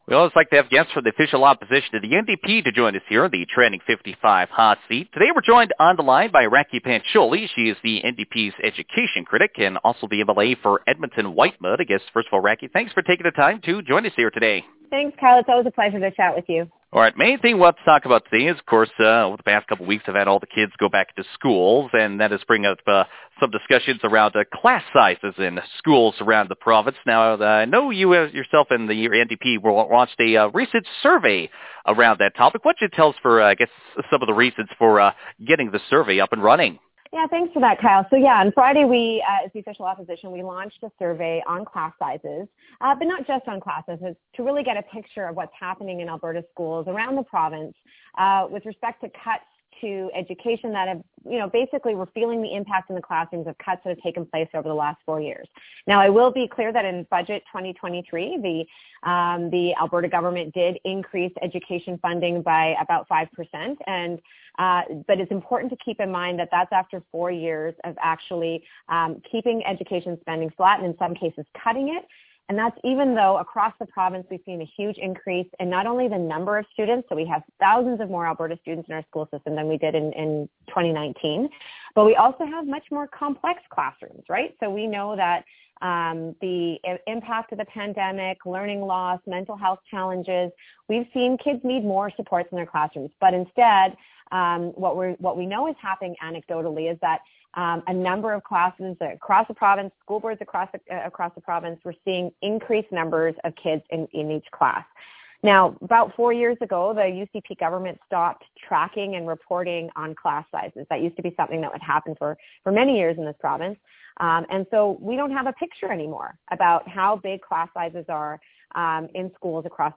Our full interview with Pancholi can be heard below.